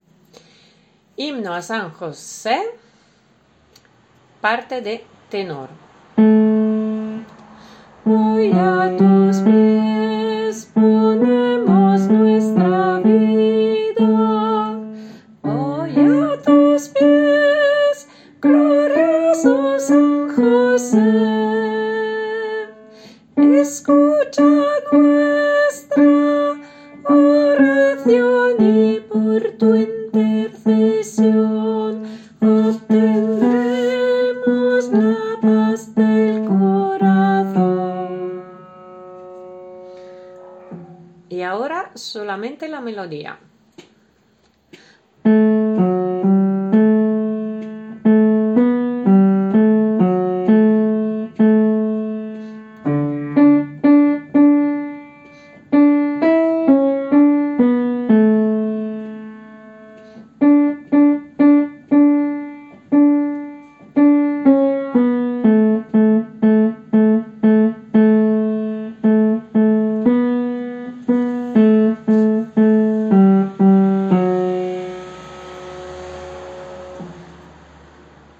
TENOR
h-sanjose-tenor.mp3